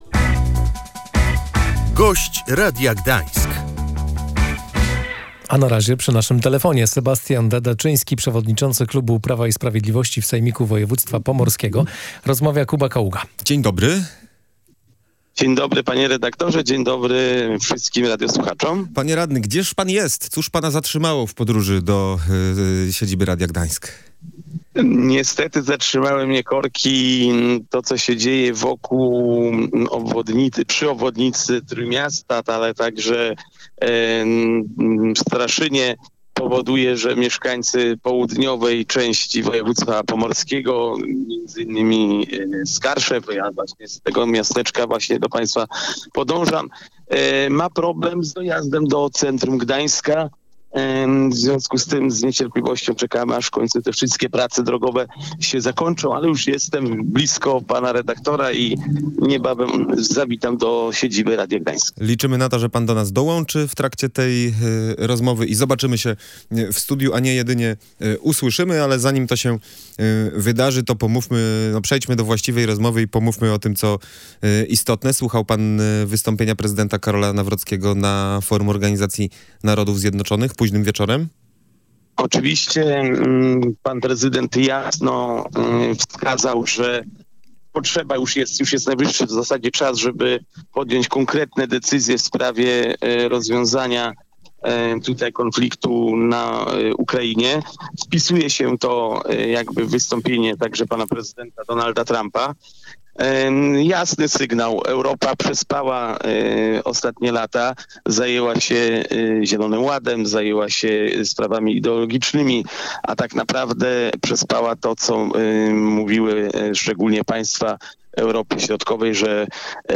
Ustawa metropolitalna dla Pomorza musi odpowiadać na potrzeby całego regionu, a nie tylko aglomeracji trójmiejskiej – mówił w Radiu Gdańsk Sebastian Dadaczyński, przewodniczący klubu Prawa i Sprawiedliwości w Sejmiku Województwa Pomorskiego.